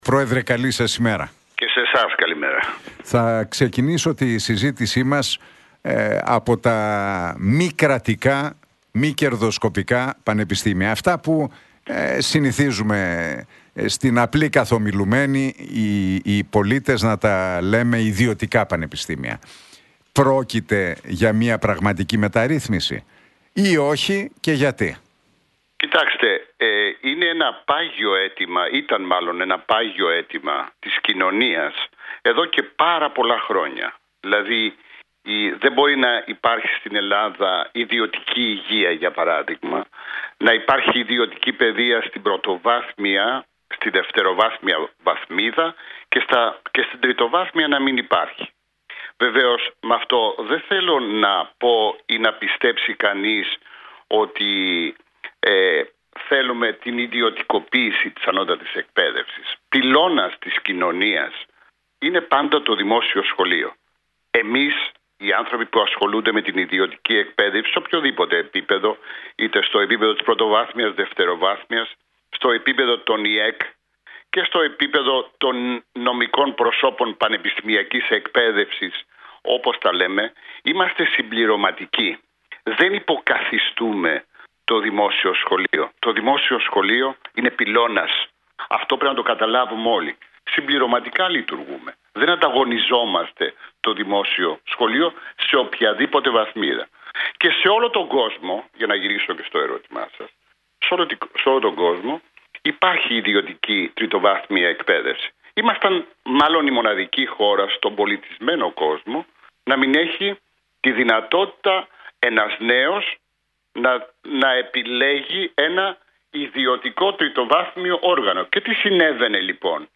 από τη συχνότητα του Realfm 97,8.